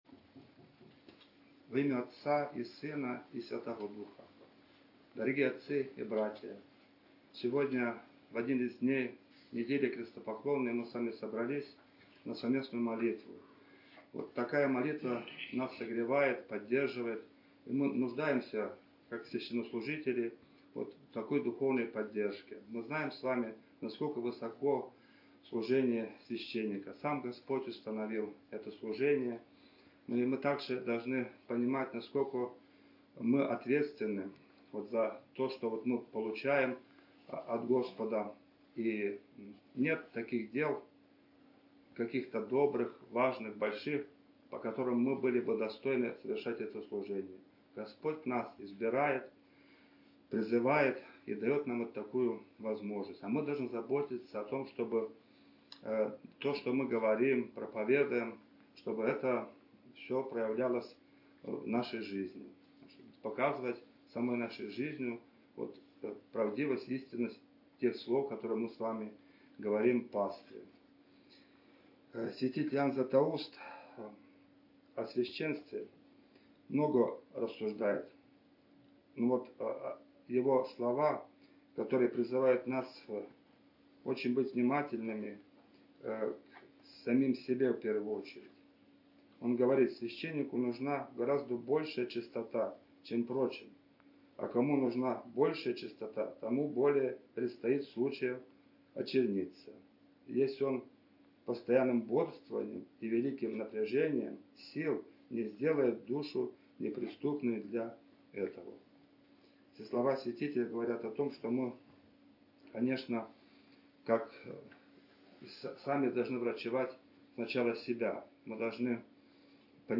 19 марта 2026 года в четверг 4-й седмицы Великого поста, Крестопоклонной, в храме Святой Живоначальной Троицы пос. Дудергоф была отслужена Вечерня и совершена исповедь духовенства Красносельского благочиния.
Проповедь